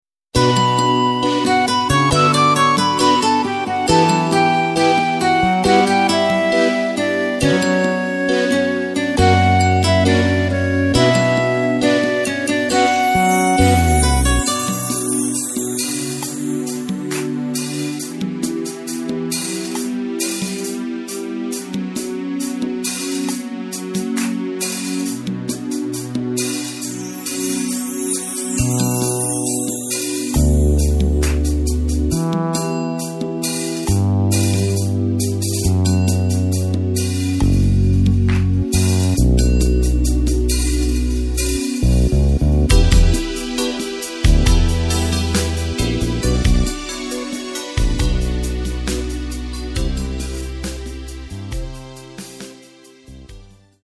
Takt:          4/4
Tempo:         136.00
Tonart:            Bm
Austropop aus dem Jahr 1989!